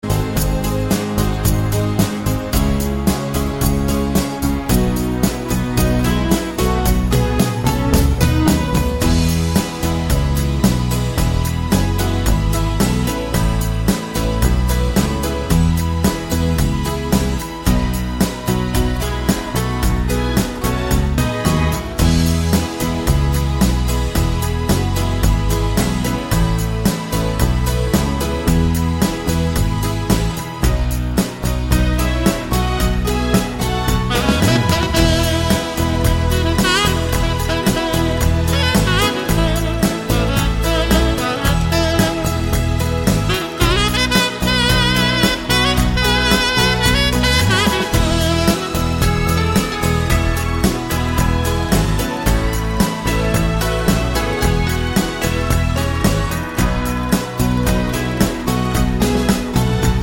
Minus All Guitars Pop (1970s) 3:05 Buy £1.50